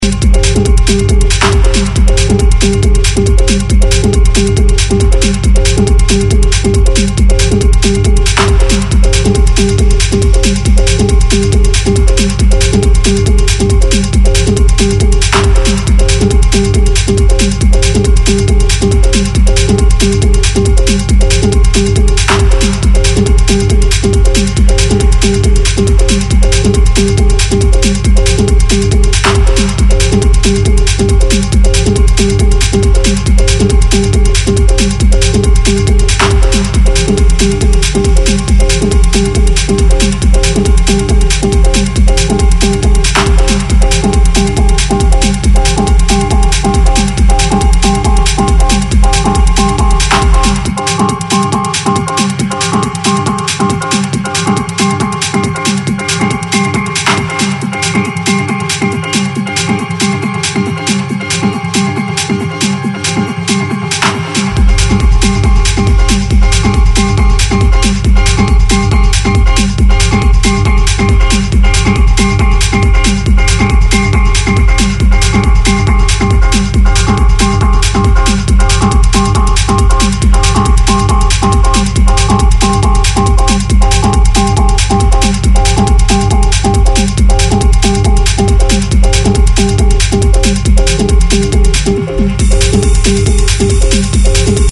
Electronic
Techno